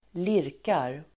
Uttal: [²l'ir:kar]